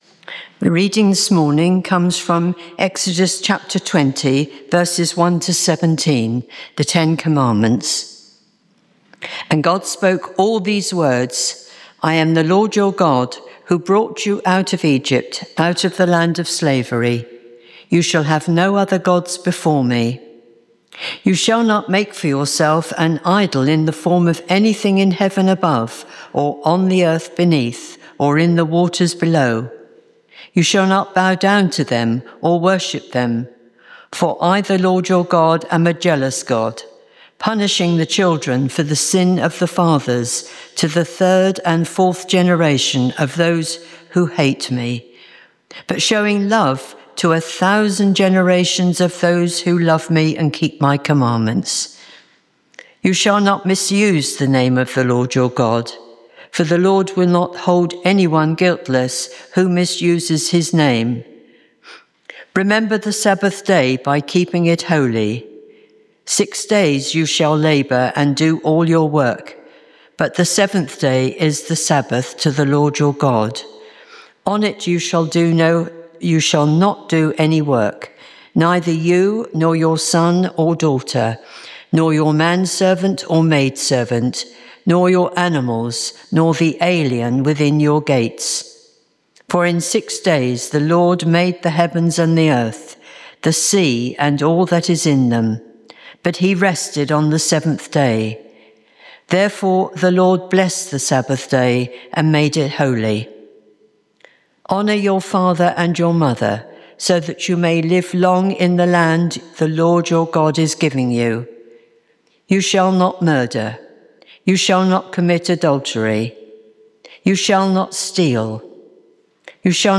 St Mary’s, Slaugham – Holy Communion Speaker